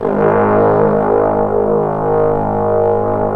Index of /90_sSampleCDs/Roland L-CD702/VOL-2/BRS_Bs.Trombones/BRS_Bs.Bone Sect